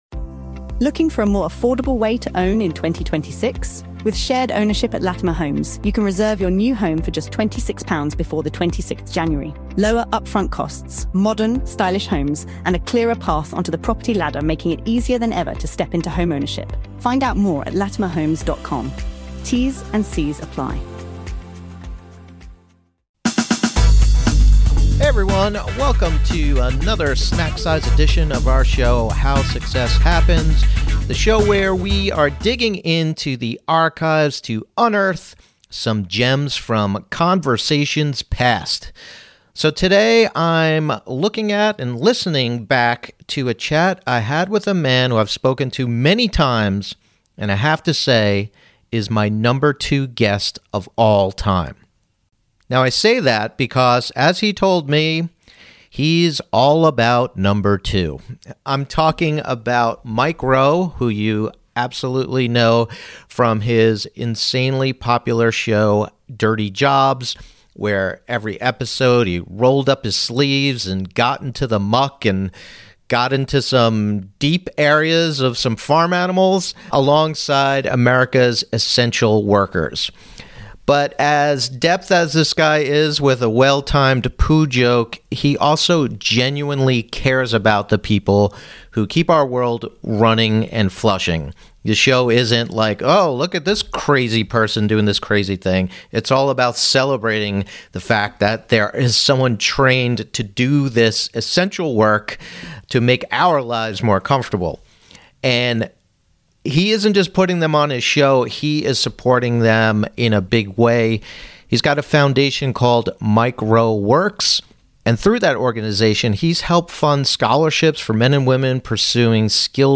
a conversation with Dirty Jobs host Mike Rowe